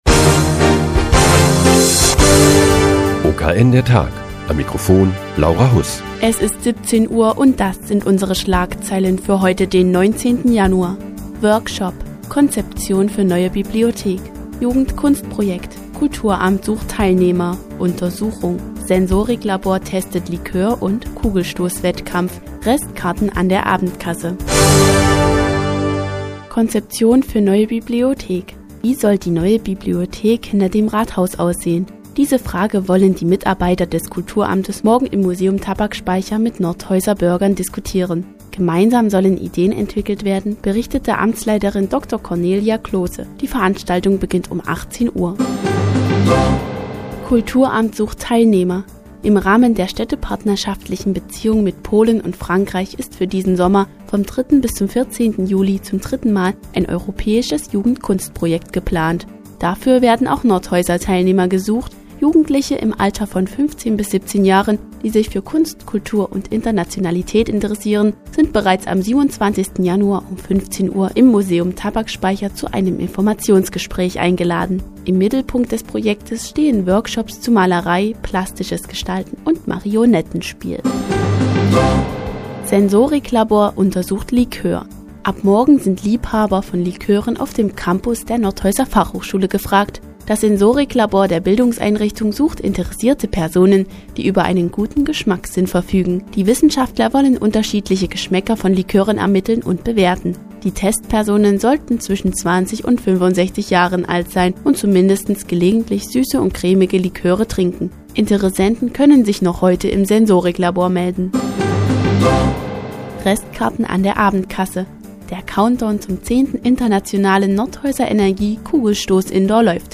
Die tägliche Nachrichtensendung des OKN ist nun auch in der nnz zu hören. Heute geht es um ein europäisches Jugendkunstprojekt und eine Likör-Untersuchung des Sensoriklabors der Fachhochschule Nordhausen.